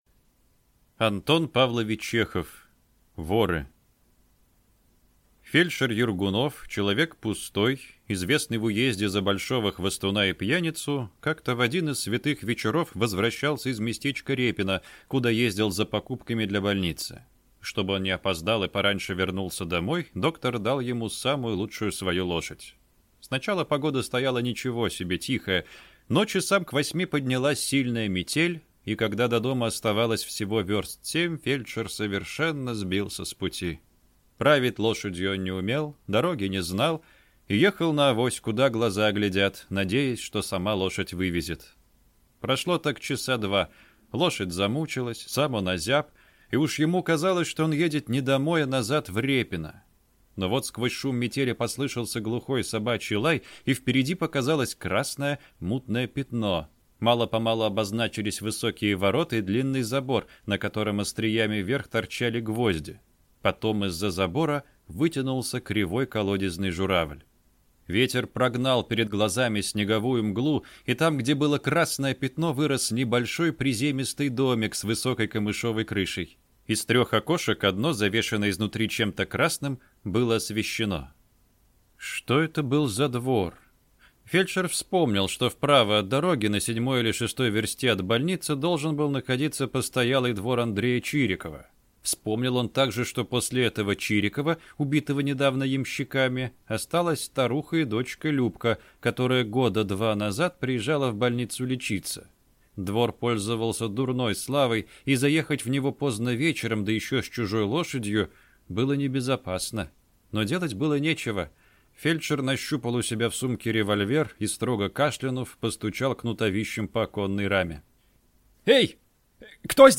Аудиокнига Воры | Библиотека аудиокниг